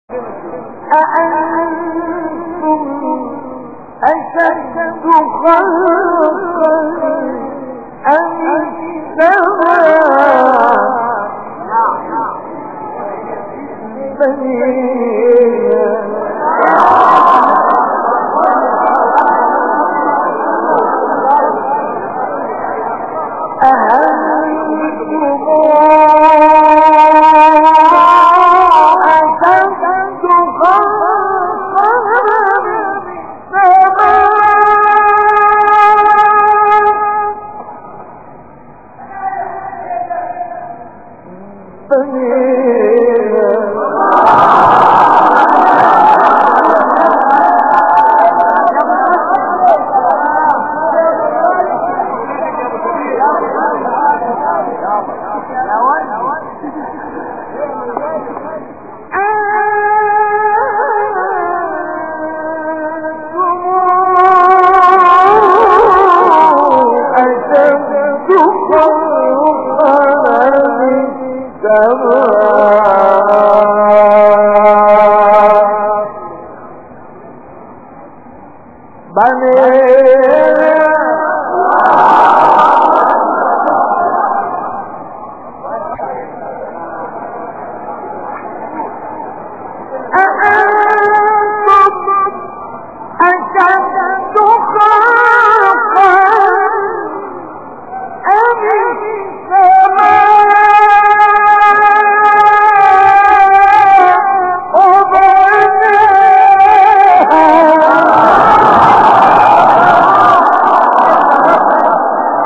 گروه فعالیت‌های قرآنی: فرازهای صوتی دلنشین با صوت قاریان برجسته مصری ارائه می‌شود.
مقطعی از سوره نازعات با صوت مصطفی اسماعیل